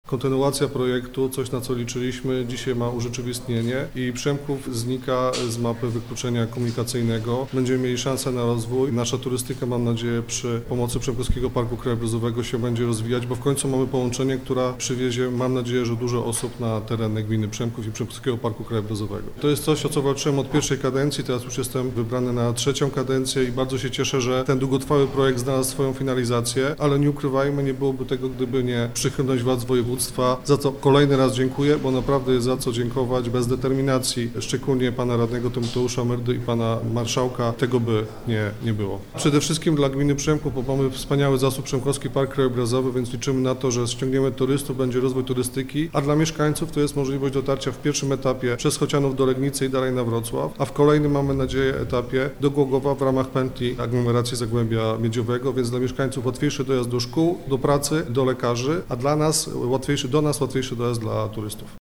Linia kolejowa to nie tylko ułatwienie dla mieszkańców Przemkowa, ale także okazja do rozwoju. Znaczenie inwestycji dla lokalnej społeczności przedstawia Jerzy Szczupak, burmistrz miasta.